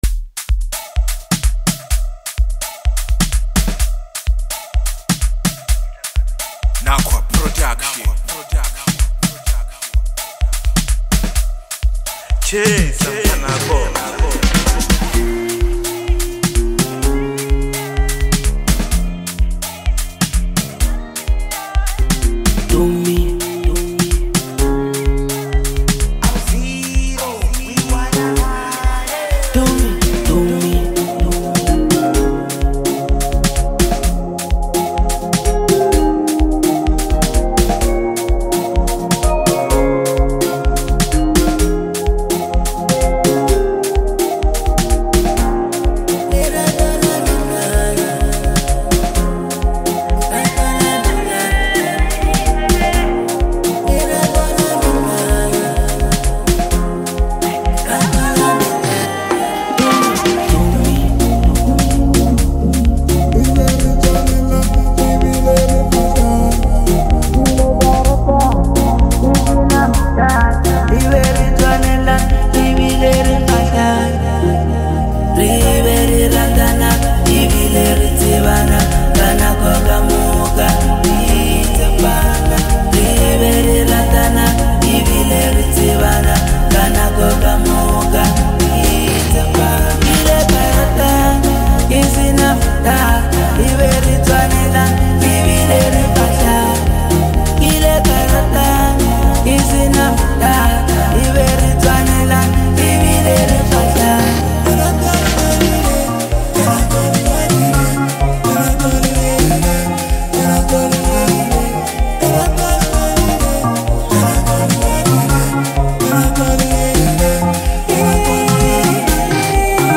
Home » Amapiano » Gqom